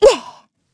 Xerah-Vox_Landing_kr.wav